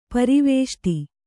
♪ pari vēṣṭi